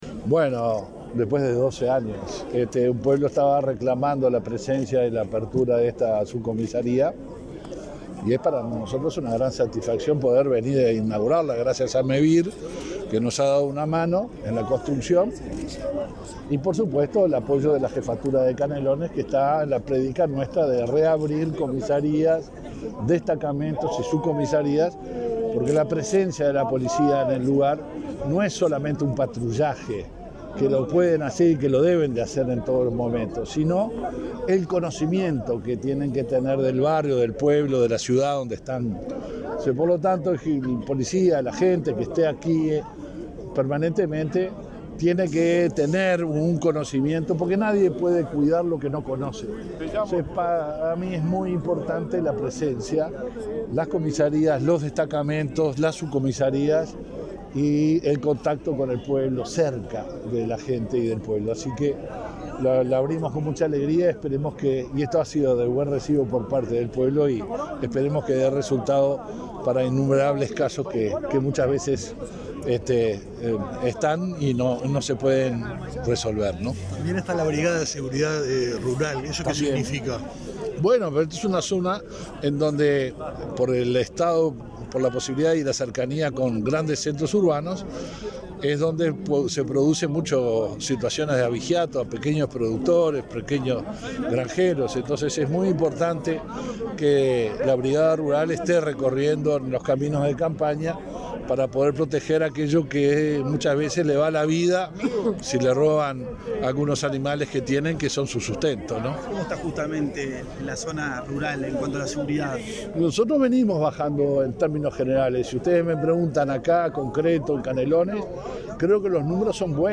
Declaraciones a la prensa del ministro del Interior, Luis Alberto Heber
Tras la inauguración de una subcomisaría en Juanicó, en Canelones, este 18 de agosto, el ministro Heber efectuó declaraciones a la prensa.